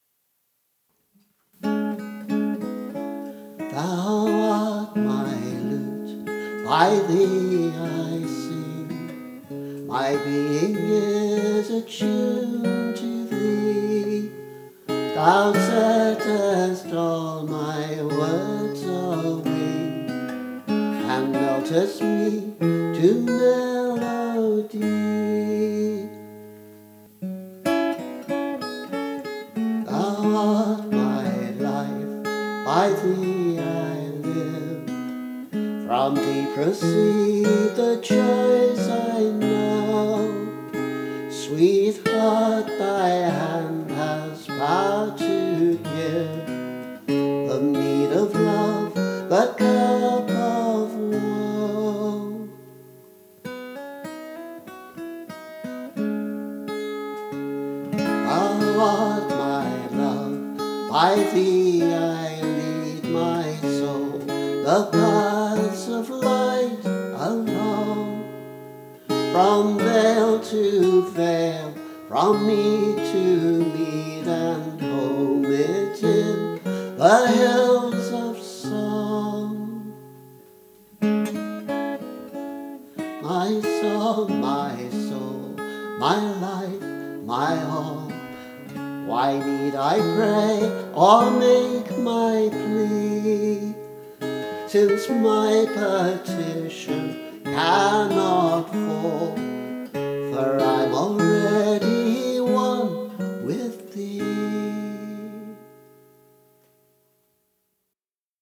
For my setting of ‘Thou Art My Lute’ I’ve used a consciously archaic arrangement to suit the tone of the poem.